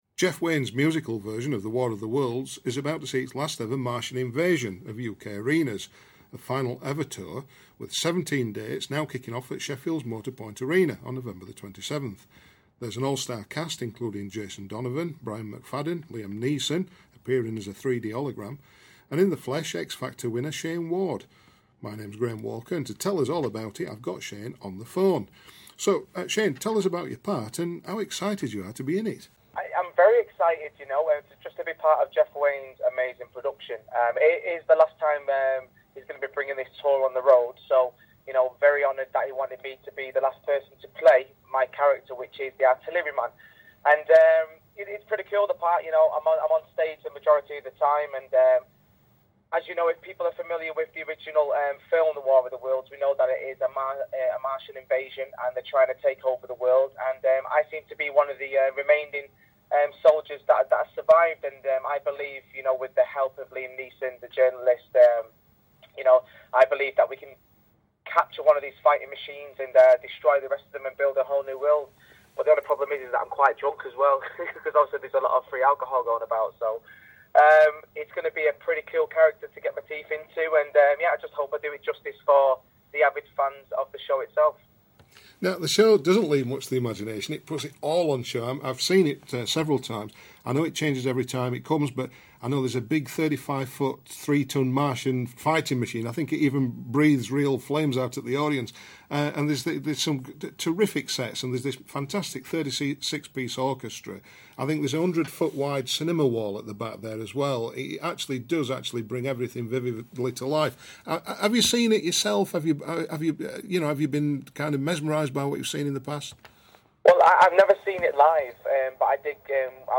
INTERVIEW: Shayne Ward in Jeff Wayne's Musical Version of War Of The Worlds